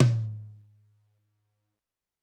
Drums_K4(08).wav